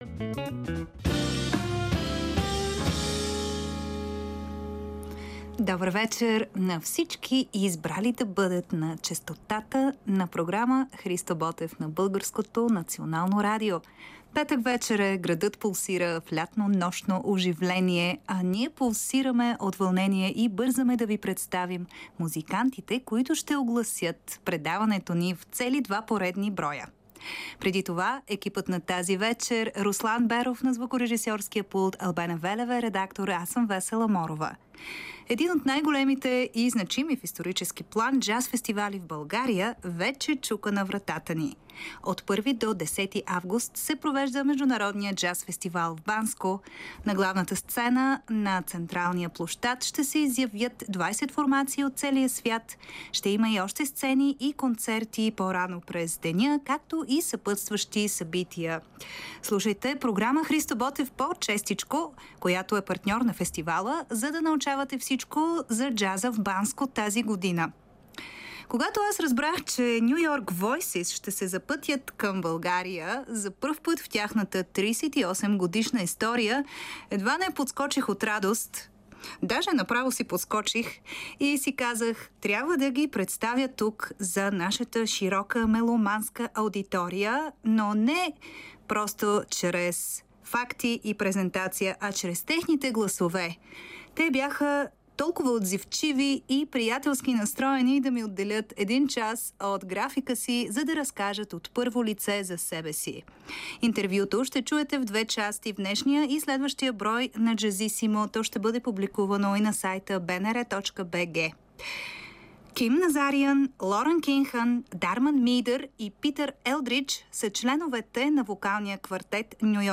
Вокалната група, оставила трайна следа във вокалния джаз